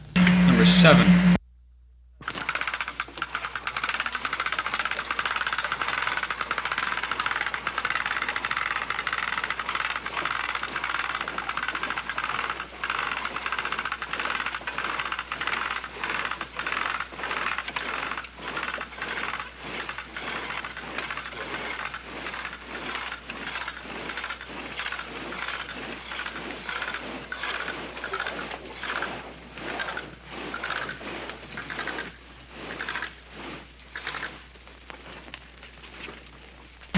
古老的吱吱声 " G2706塑料链条滑轮
描述：轻质塑料链被拉上滑轮。物体的一些背景噪声和声音。大量点击和噼啪声。长拉。
我已将它们数字化以便保存，但它们尚未恢复并且有一些噪音。